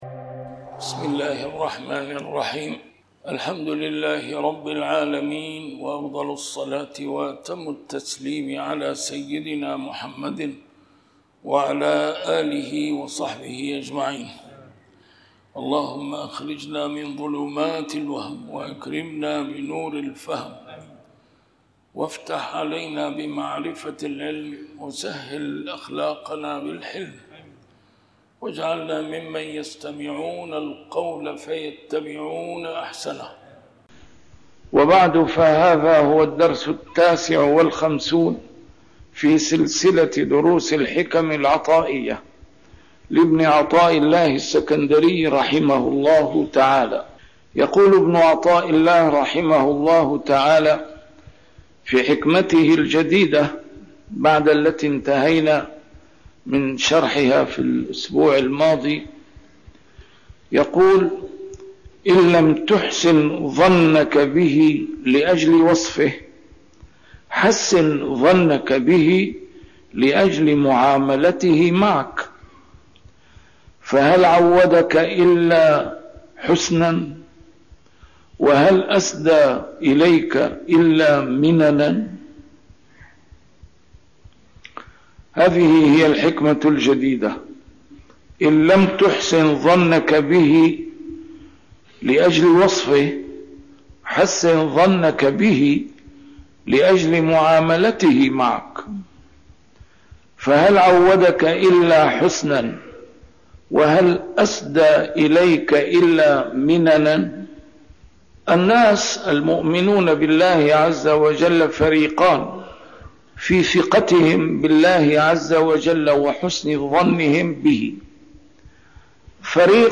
A MARTYR SCHOLAR: IMAM MUHAMMAD SAEED RAMADAN AL-BOUTI - الدروس العلمية - شرح الحكم العطائية - الدرس رقم 59 شرح الحكمة 40